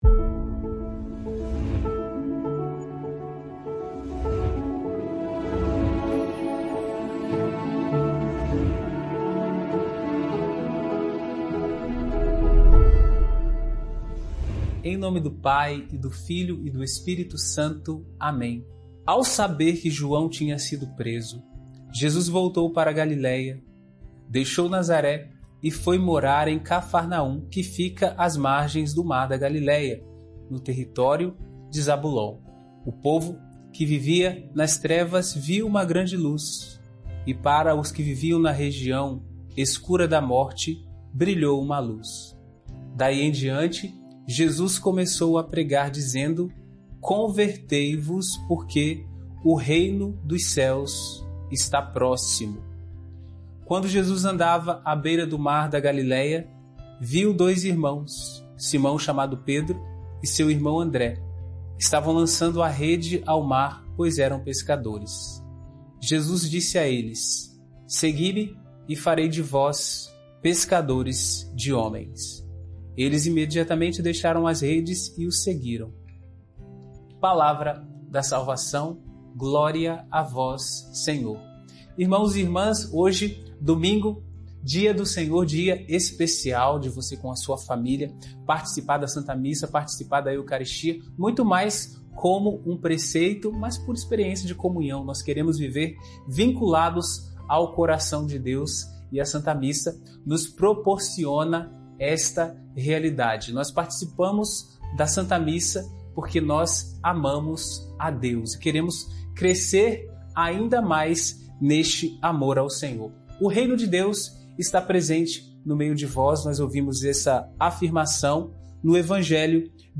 O Reino de Deus e o chamado à conversão. Mateus 4 - Homilia diária